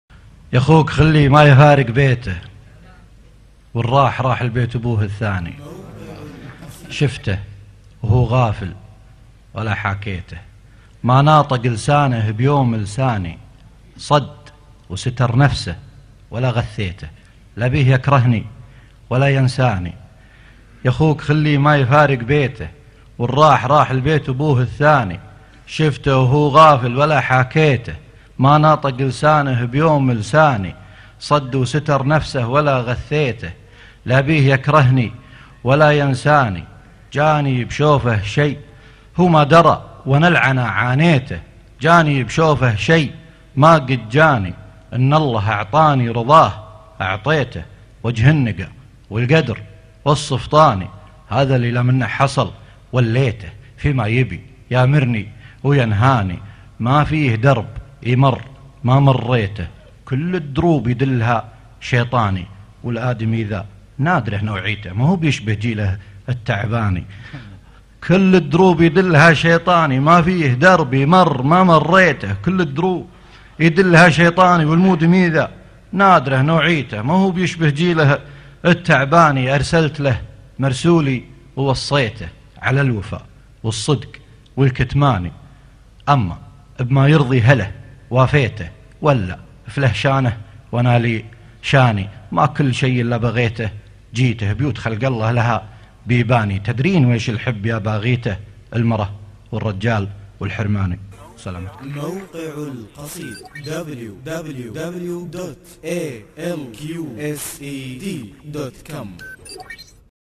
الحـب الصحــيح - مهرجان أهل القصيد السادس 2011   30 نوفمبر 2011